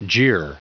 Prononciation du mot jeer en anglais (fichier audio)
Prononciation du mot : jeer